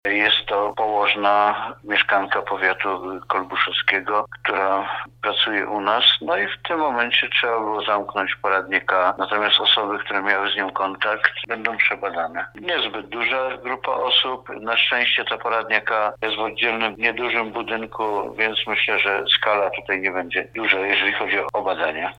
Mówi starosta powiatu tarnobrzeskiego Jerzy Sudoł.